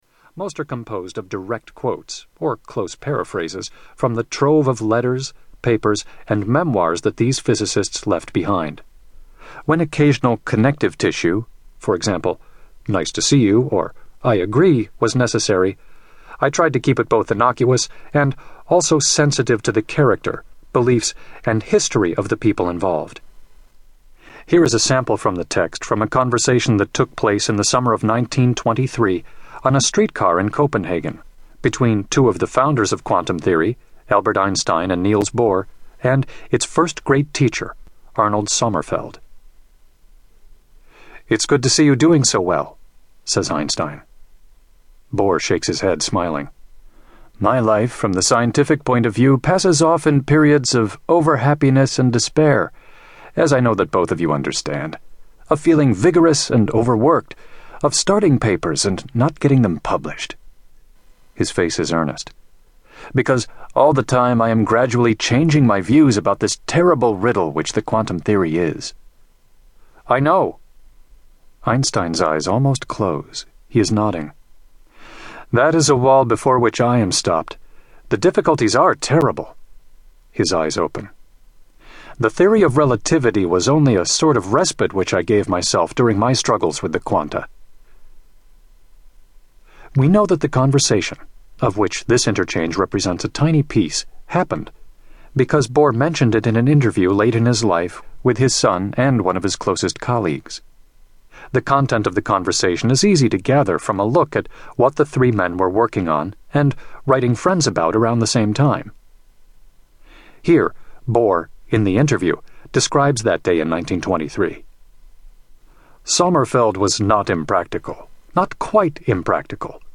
Tags: Quantum Physics Audio Books Quantum Physics Quantum Physics clips Physics Quantum Physics sound clips